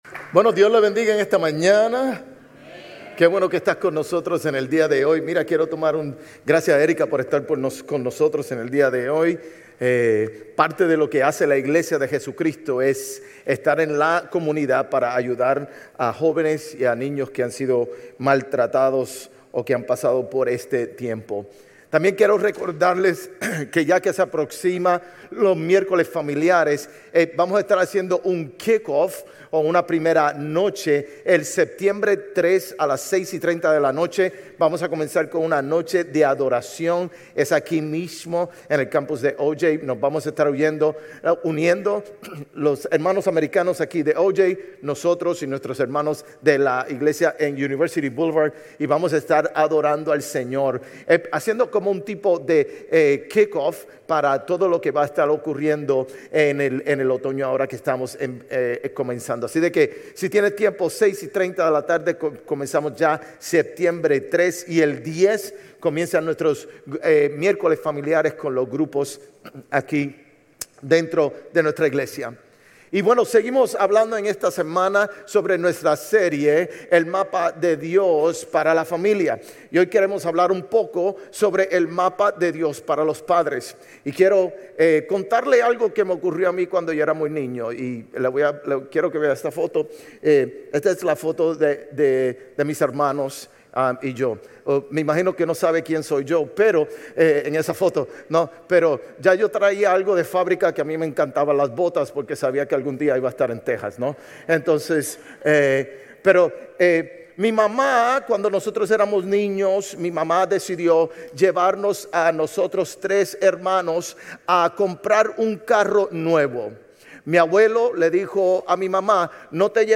Sermones Grace Español 8_24 Grace Espanol Campus Aug 24 2025 | 00:41:44 1x 00:00 / 00:41:44 Subscribe Share RSS Feed Share Link Embed